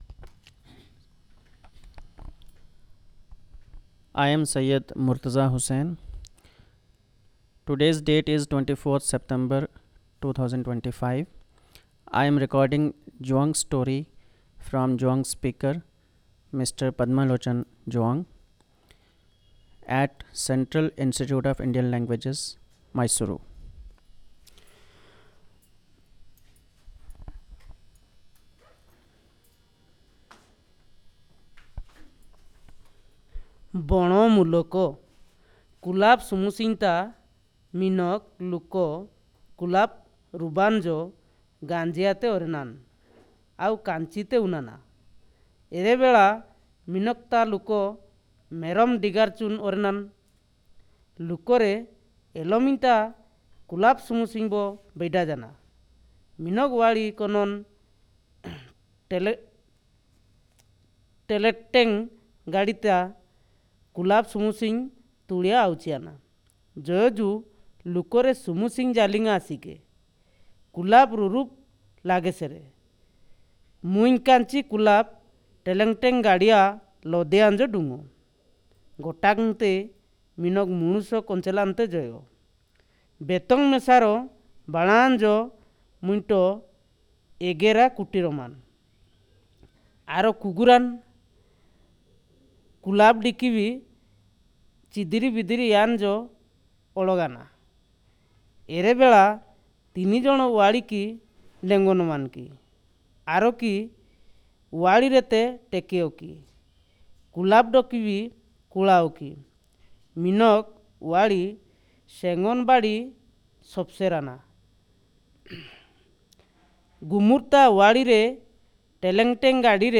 Elicitation of story, informant narrated after watching from Youtube.